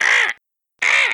sounds_vulture.ogg